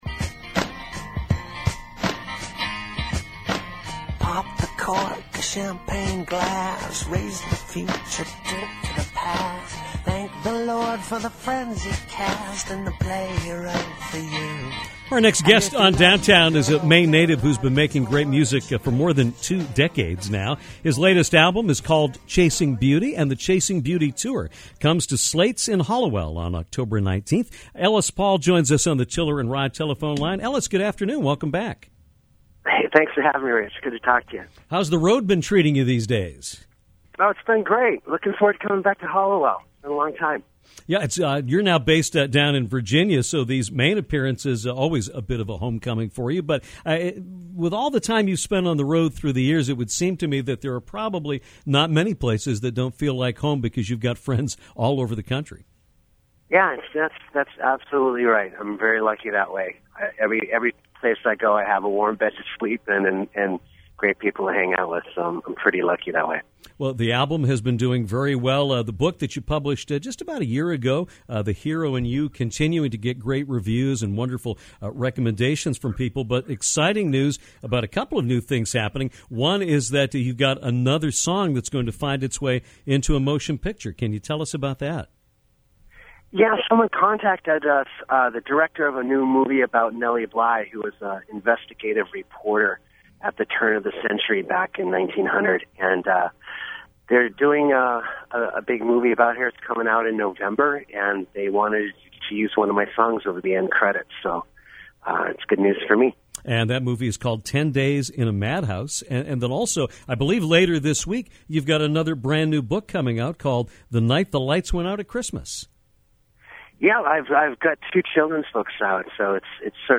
Singer and songwriter Ellis Paul came on Downtown to talk about his career and upcoming appearance in Hallowell, Maine. He talked about a few of his songs being feature in movies soon and how excited he is to play in Maine again after he relocated down South. Paul also briefly reminisced about his cross country days in the state of Maine.